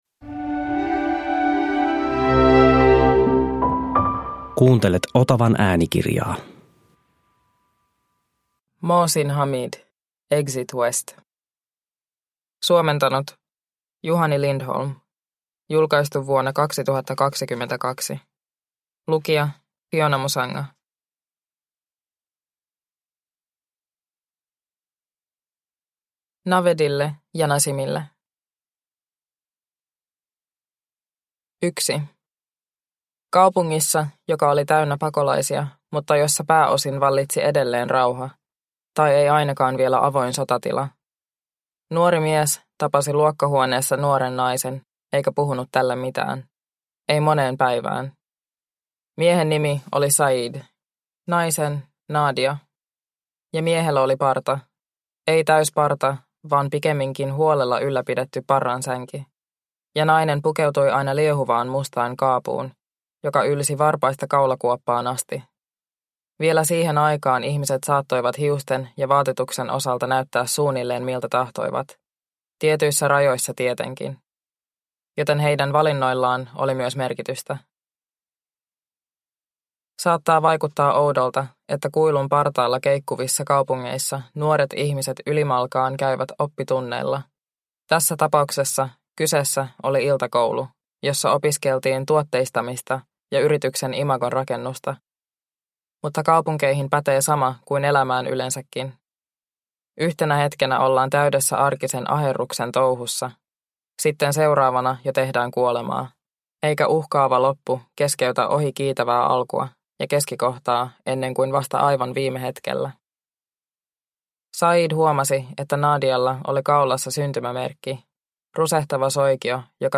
Exit west – Ljudbok – Laddas ner